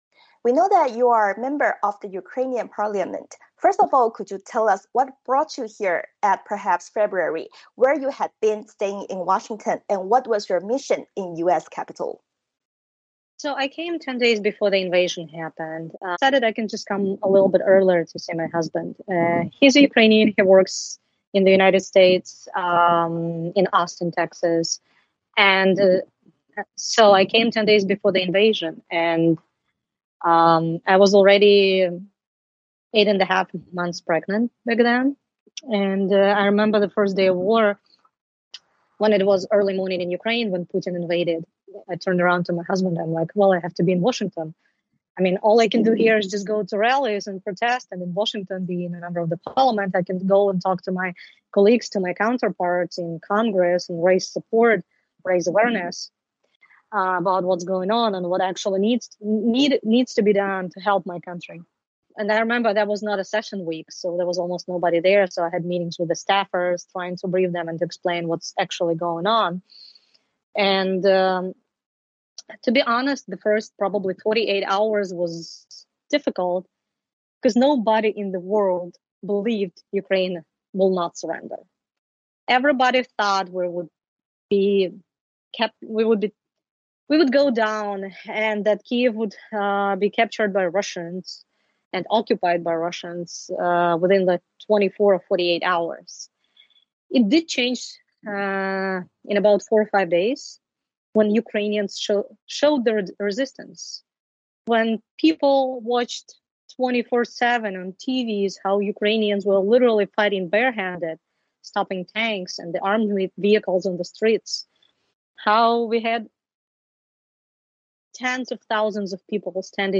VOA专访: 乌克兰议员分娩在即 奔走美国国会争取支持